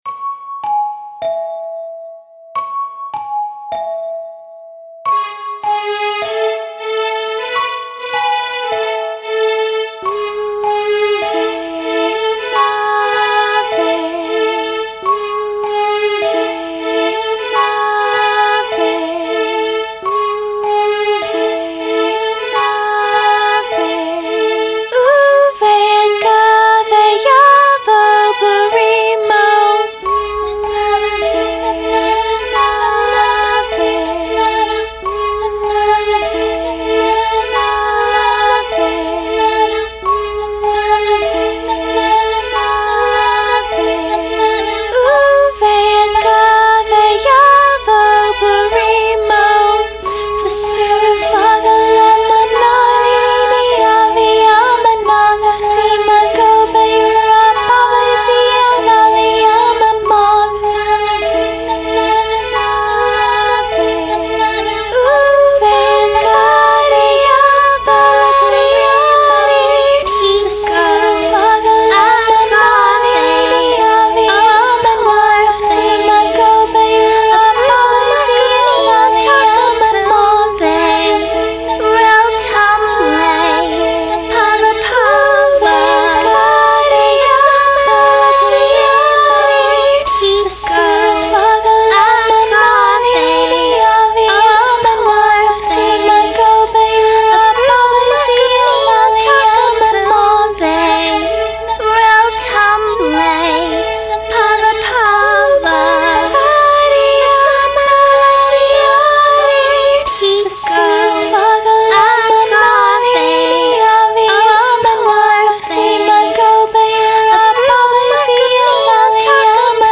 Posted in Dubstep, Other, Vocals Comments Off on